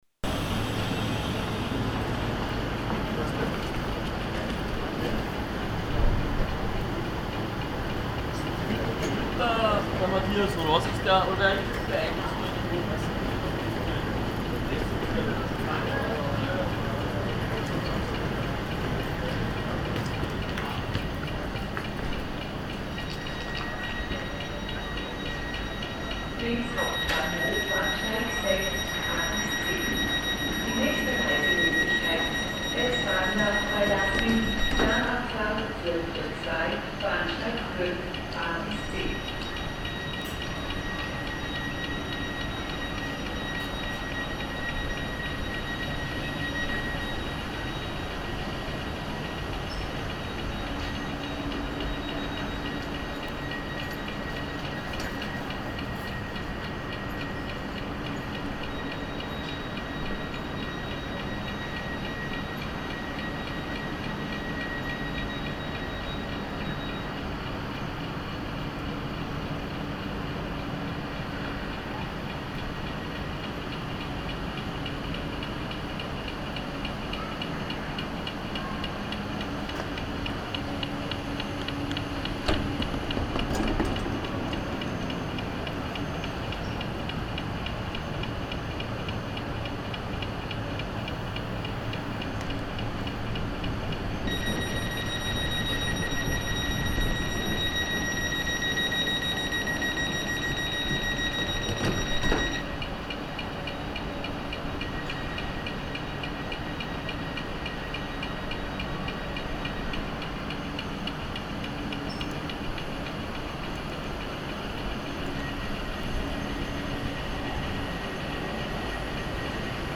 Als Gutes Beispiel für barrierefreies Reisen finde ich das Auffindungssignal bei den Türen der Zuggarnituren der Westbahn. Das Hörbeispiel wurde am Hauptbahnhof Linz aufgenommen.
Hörbeispiel Auffindungssignal bei Zügen der Westbahn: